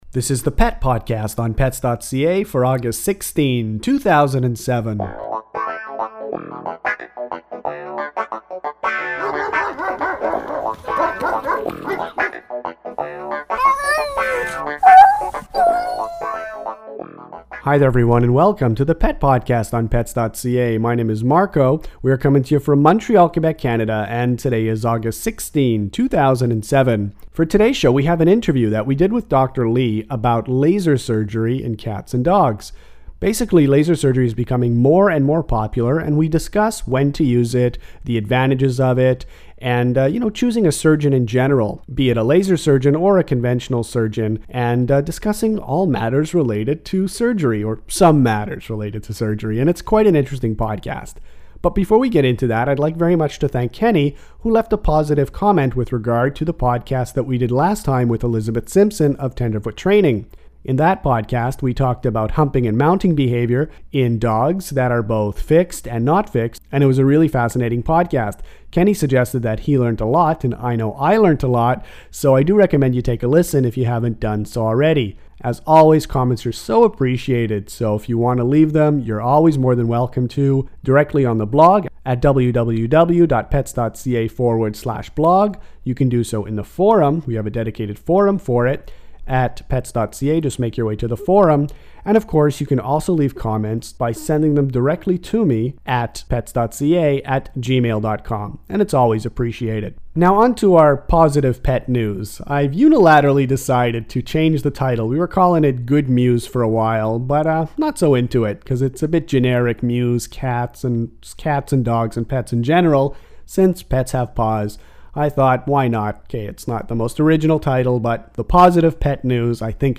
Laser surgery in cats dogs and pets – Pet podcast #24 – Interview